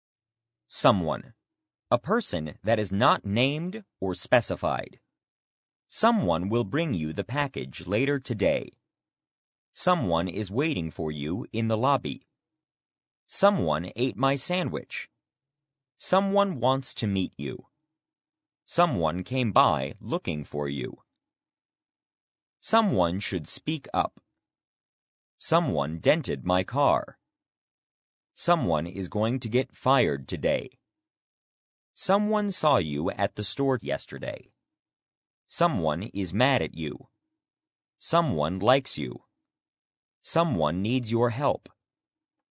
someone (pro): a person that is not named or specified < previous > next Listen to the Lesson | Listen with pause Example sentences: " Someone will bring you the package later today.